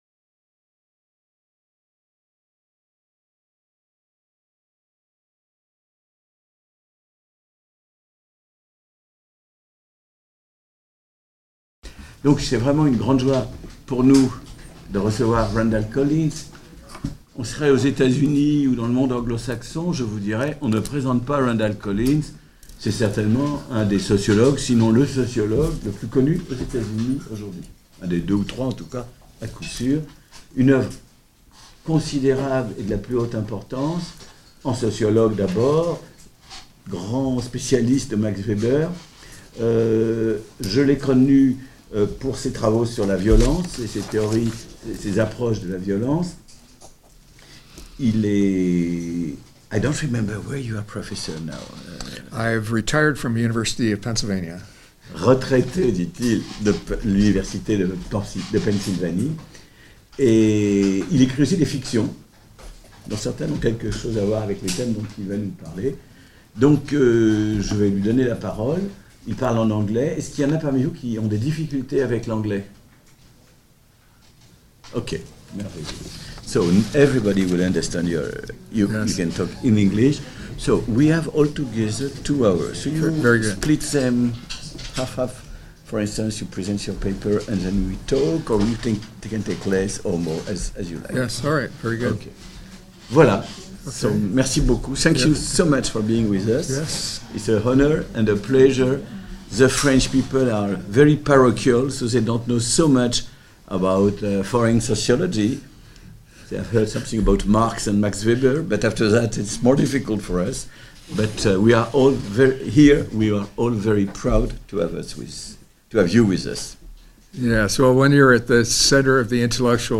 Séminaire Violence et sortie de la violence - 31 mai 2018 La puissance croissante des armes, combinée à la surveillance aérienne, aux satellites, aux capteurs et à la communication mondiale a induit un éclatement des champs de batailles.